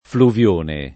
Fluvione [ fluv L1 ne ]